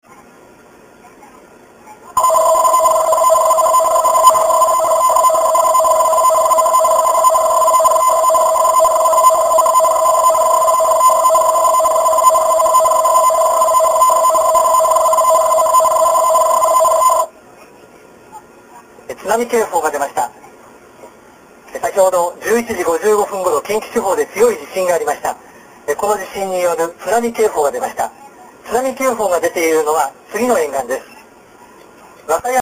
ラジオ放送 ラジオ音声がそのまま放送されます※
※ＮＨＫラジオの放送例